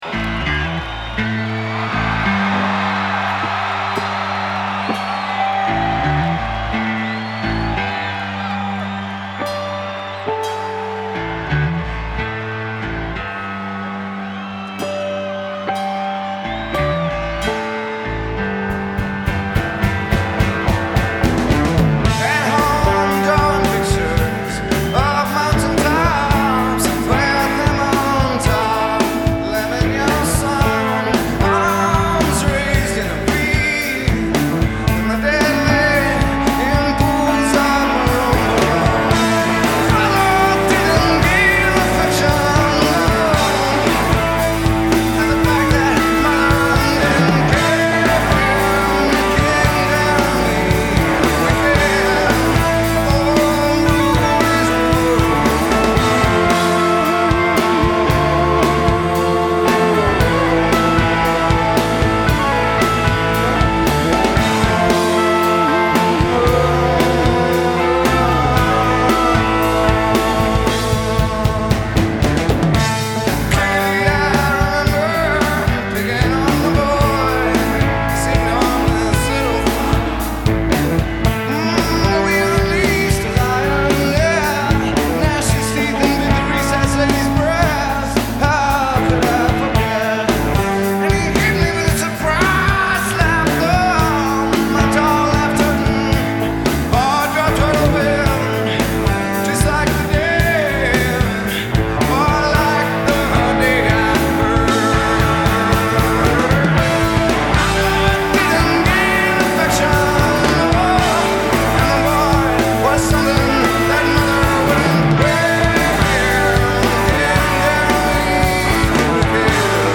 Genre: Grunge